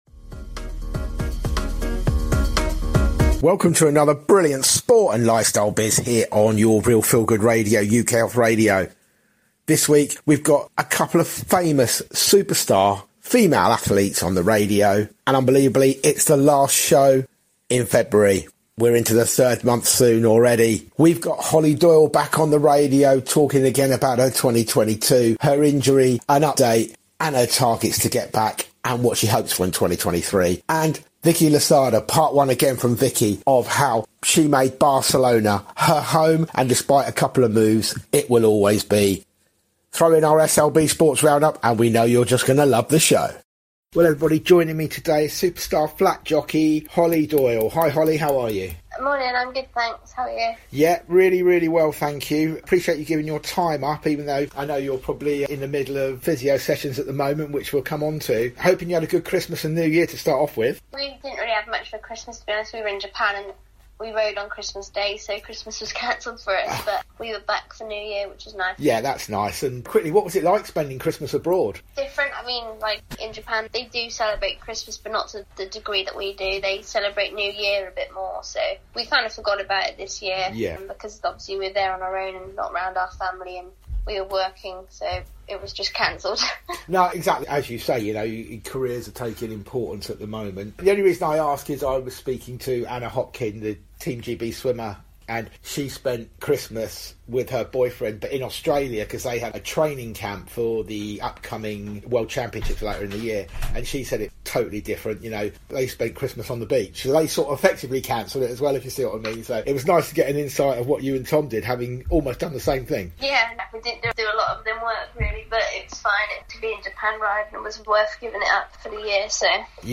We will bring you features on youth foundations and the development areas for youngsters plus interviews with inspirational women and children.